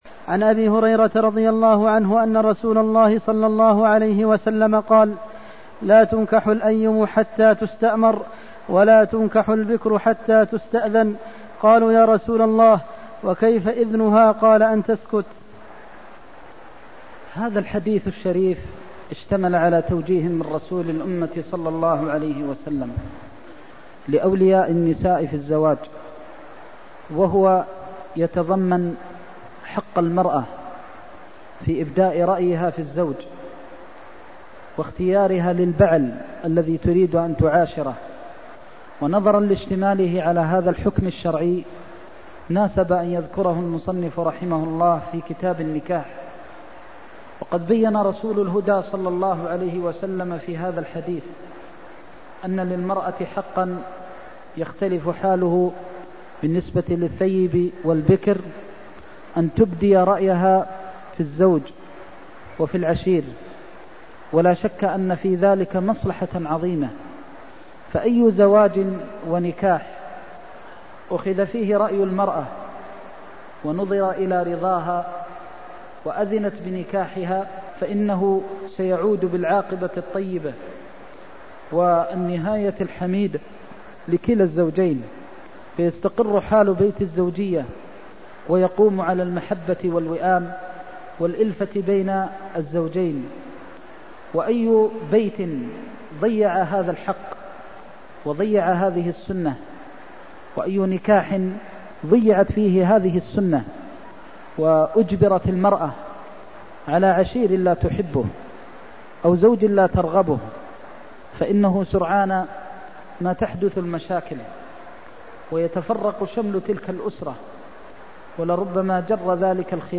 المكان: المسجد النبوي الشيخ: فضيلة الشيخ د. محمد بن محمد المختار فضيلة الشيخ د. محمد بن محمد المختار لا تنكح الأيم حتى تستأمر ولا تنكح البكر حتى تستأذن (292)